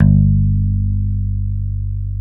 Index of /90_sSampleCDs/Roland L-CD701/BS _Rock Bass/BS _Dan-O Bass